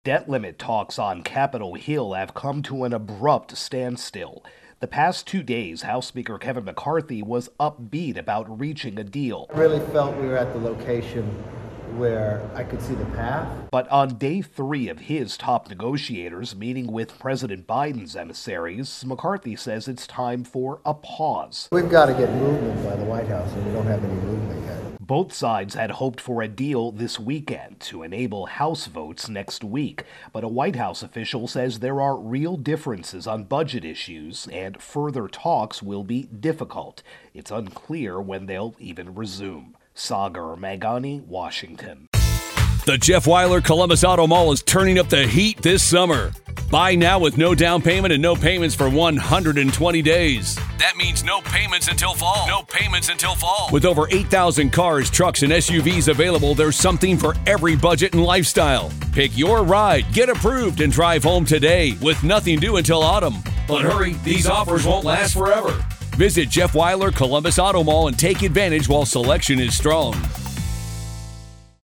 AP Washington correspondent